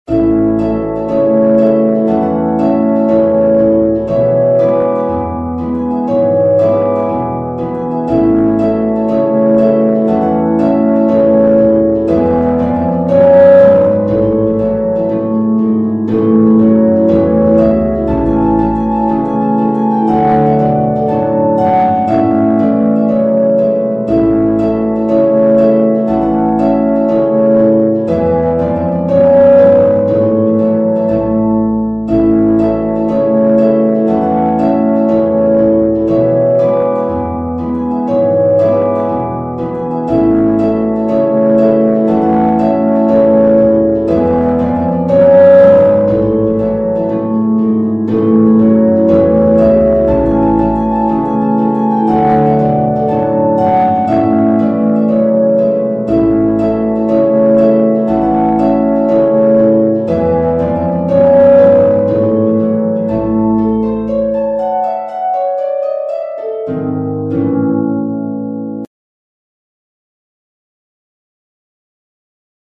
traditional
Parts 1 and 2 Arranged for Beginner
LEVER HARP or PEDAL HARP
Part 1 is the melody of the tune.